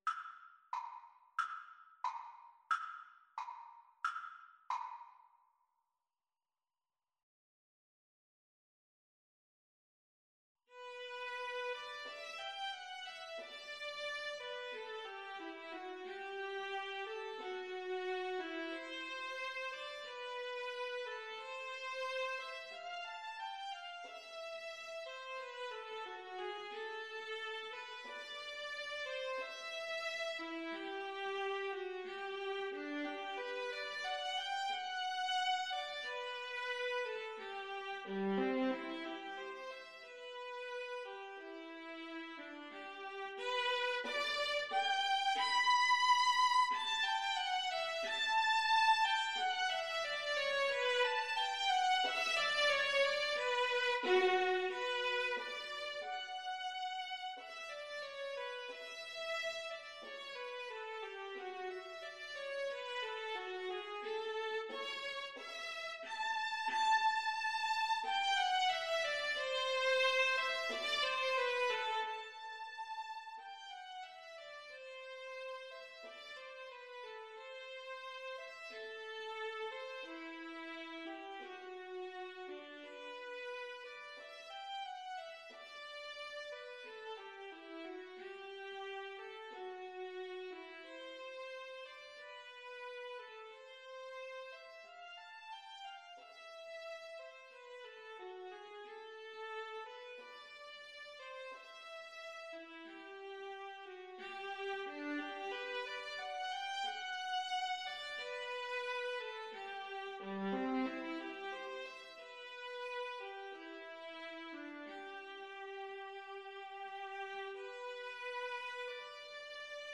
Play (or use space bar on your keyboard) Pause Music Playalong - Player 1 Accompaniment reset tempo print settings full screen
~ = 100 Allegretto con moto =90
G major (Sounding Pitch) (View more G major Music for Viola Duet )
Classical (View more Classical Viola Duet Music)